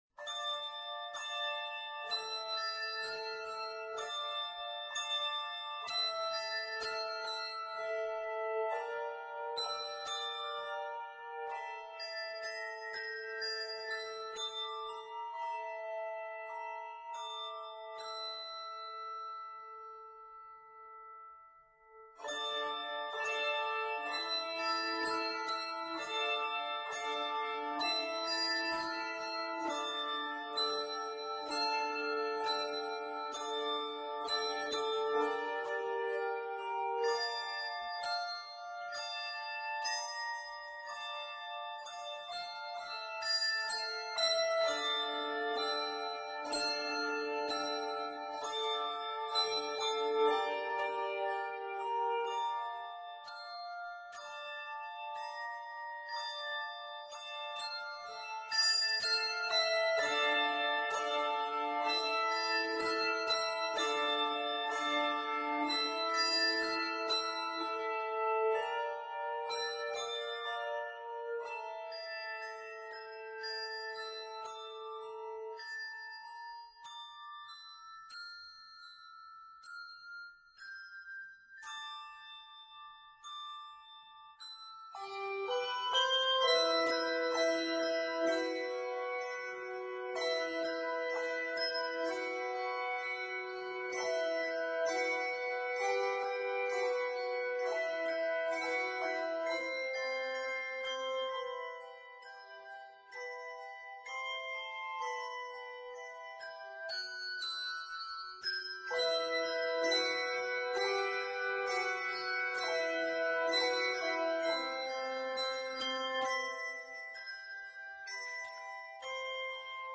traditional Japanese melody